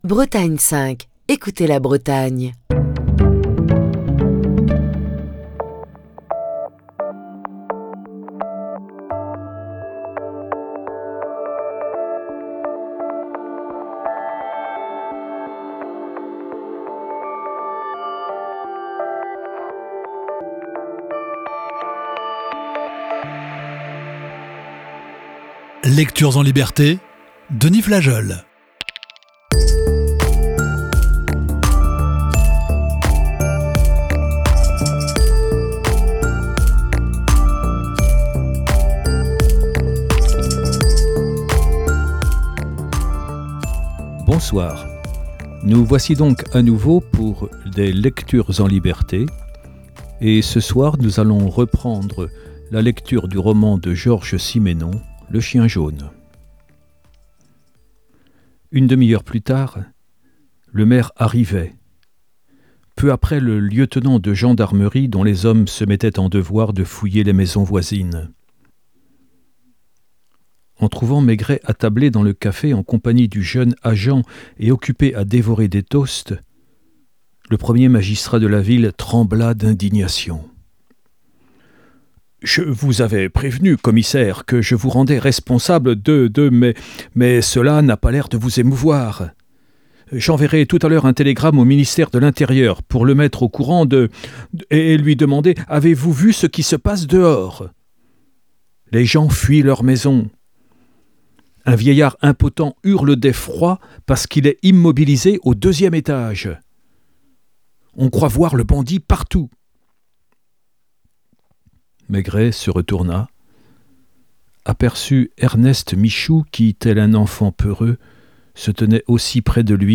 lit un classique du roman policier